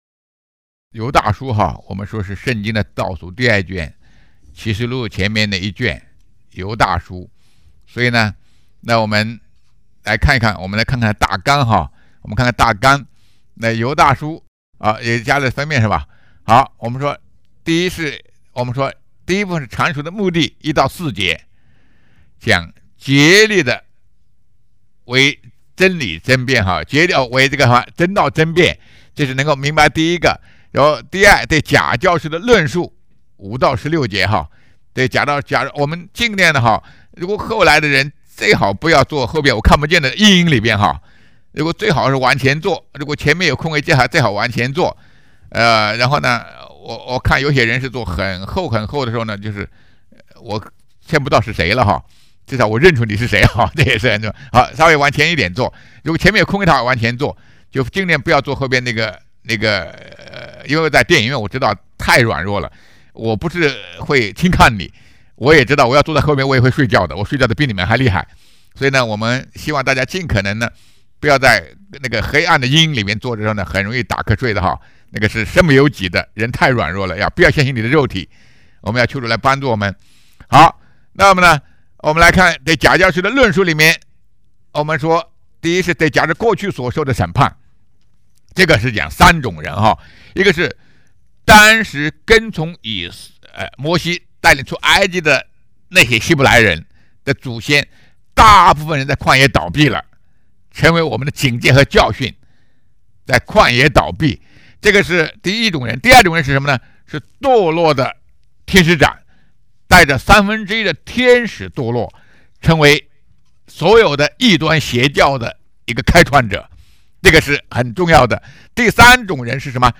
2024年12月15日 上午11:45 作者：admin 分类： 犹大书圣经讲道 阅读(1.55K